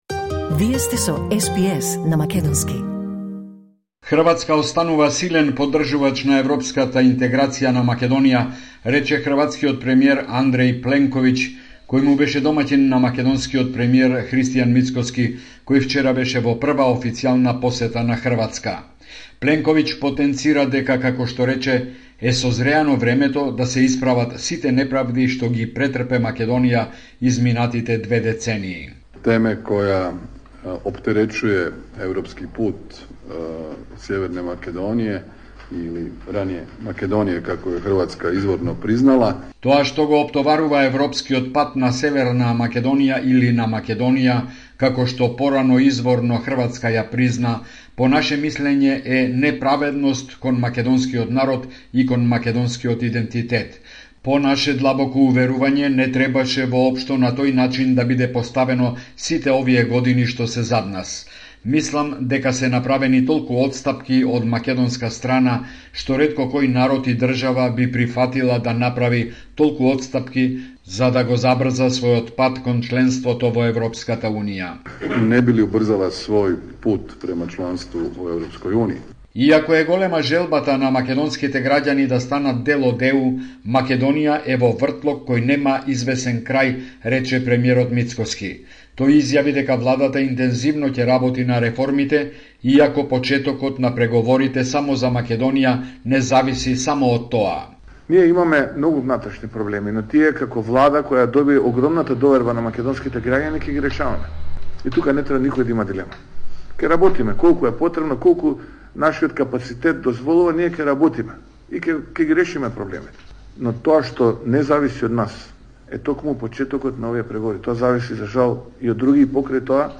Извештај од Македонија 30 август 2024
Homeland Report in Macedonian 30 August 2024